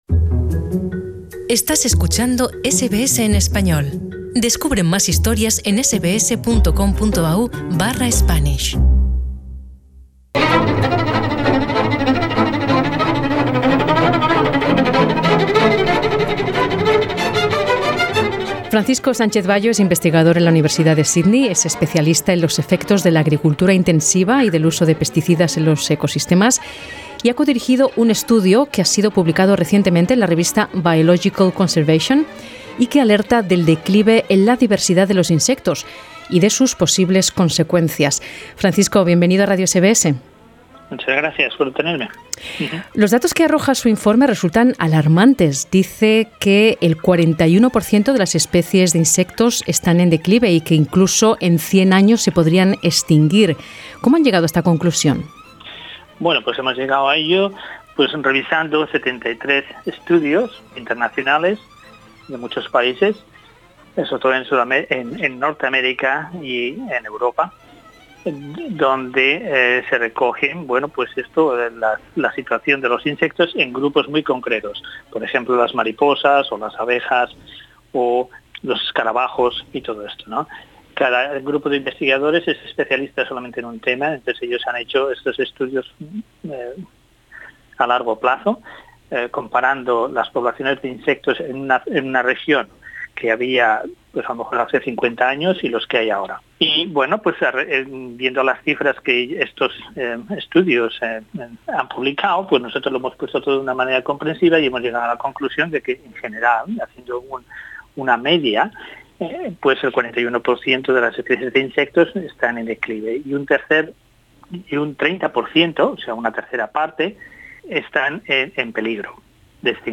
Escucha la entrevista con SBS Spanish.